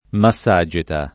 vai all'elenco alfabetico delle voci ingrandisci il carattere 100% rimpicciolisci il carattere stampa invia tramite posta elettronica codividi su Facebook massageta [ ma SS#J eta ; alla greca ma SS a J$ ta ] etn. stor.; pl. m. ‑ti